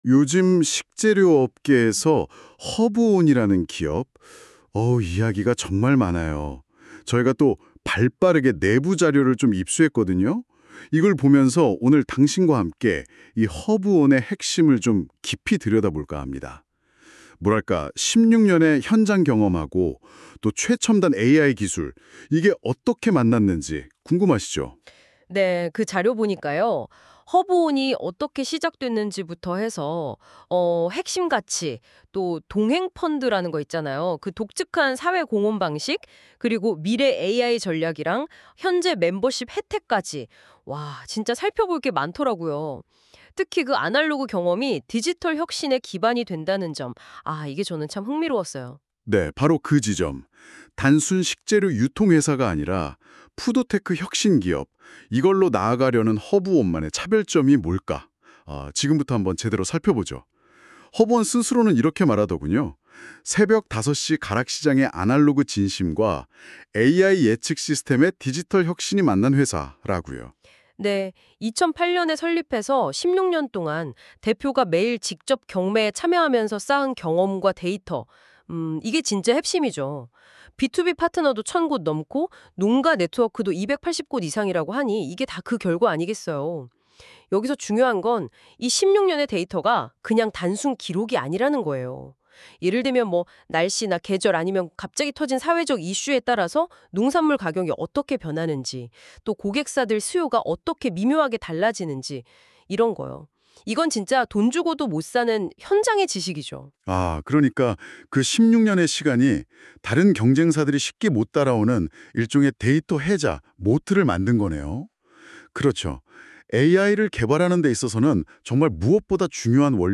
Google의 NotebookLM을 활용해 허브온의 16년 스토리를 생생한 팟캐스트로 제작했습니다. 두 진행자가 들려주는 허브온의 진솔한 이야기를 만나보세요.
이 팟캐스트는 허브온의 실제 스토리와 데이터를 바탕으로 Google NotebookLM AI 기술을 활용해 제작되었습니다.
3. 🎵 AI 음성으로 자연스러운 대화 구성